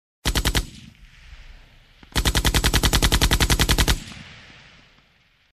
Звуки пулемёта
Пулемётная очередь вдали